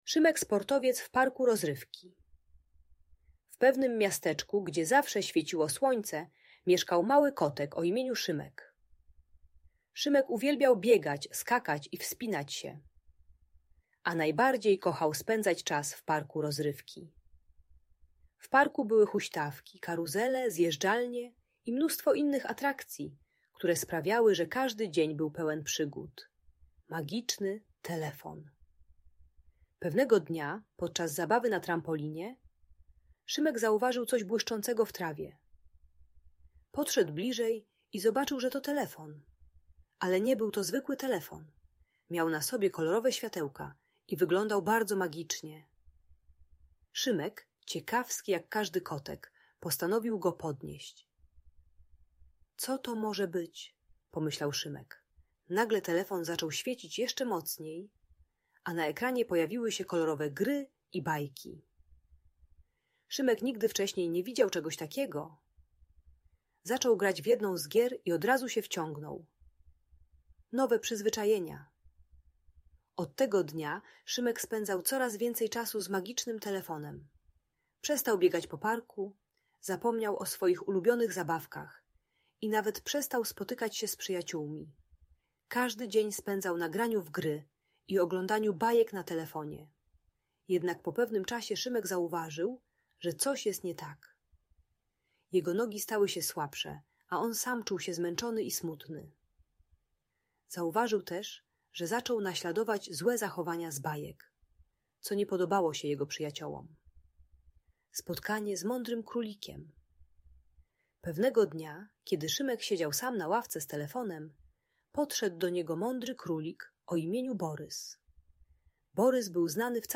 Historia Szymka Sportowca w Parku Rozrywki - Audiobajka